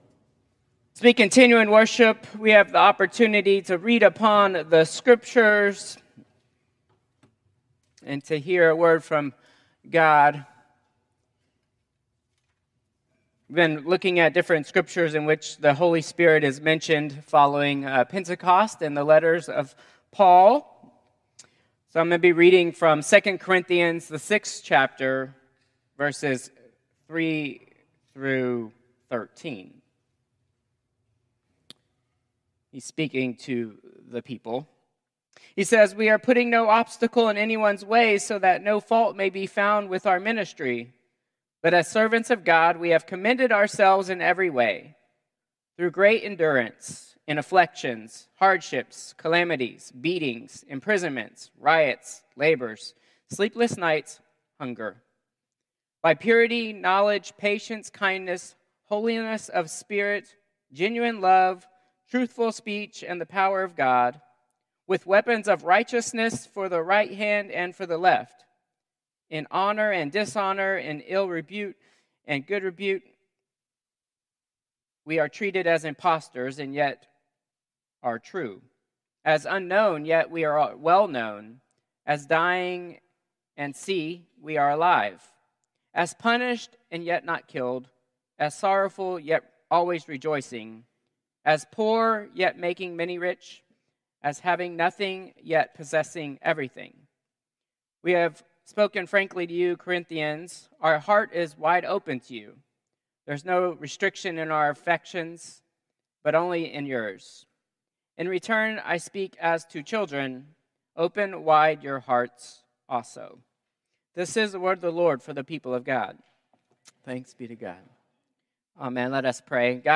Combined Service 6/29/2025